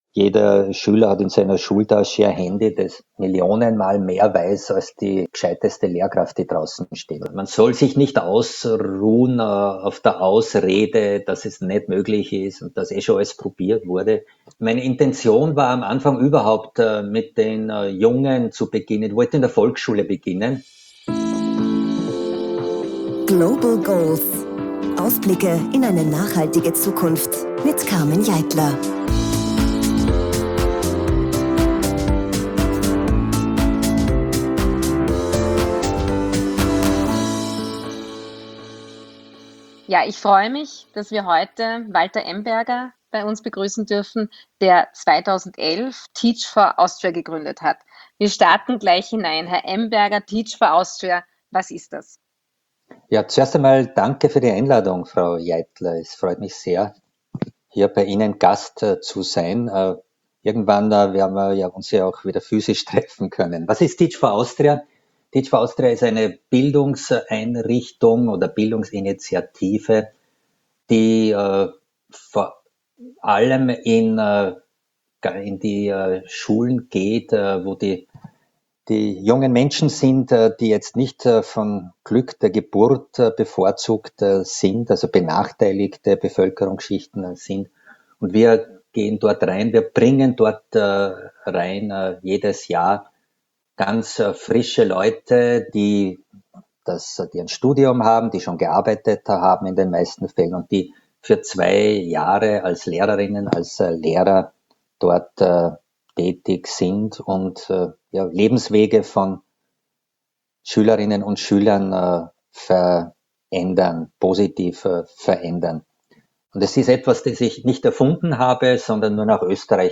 Carmen Jeitler, SDG-Sprecherin im Nationalrat, bittet innovative Unternehmerinnen und Unternehmer zu Wort. In spannenden Gesprächen erzählen diese was ihren Antrieb die Welt zu verbessern ausmacht und welche klugen Lösungen sie für uns parat haben.